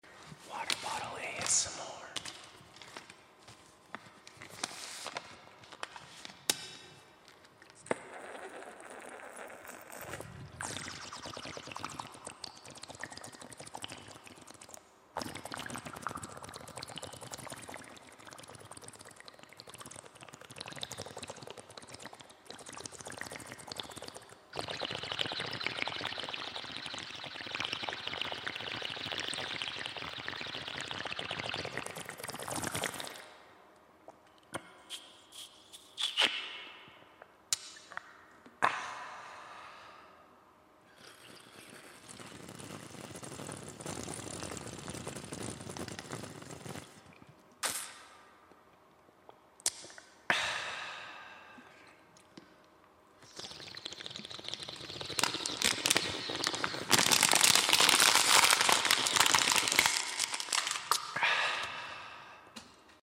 Who knew drinking water could make the perfect relaxing ASMR sounds.